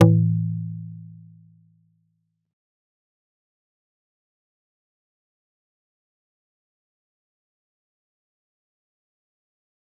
G_Kalimba-A2-mf.wav